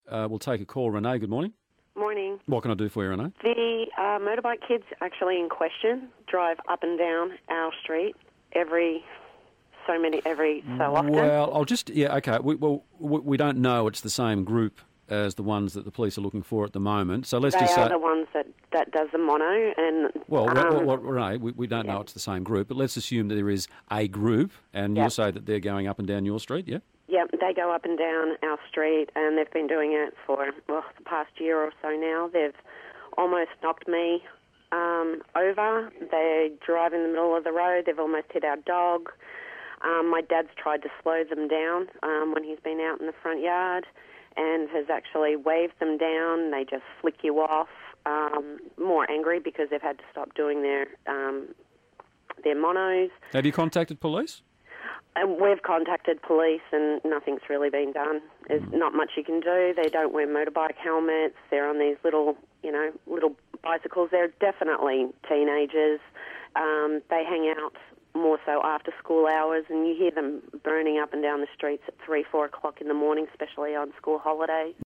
Caller says teen monkey bike riders wreak havoc in her street